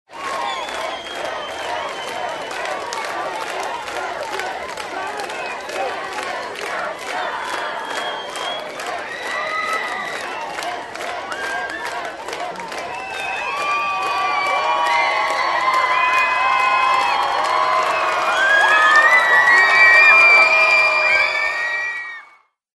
Звуки фанатов на концерте
Здесь вы найдете громкие крики поддержки, ритмичные аплодисменты и эмоциональные реакции зала.
Звук: зрители требуют выступления на бис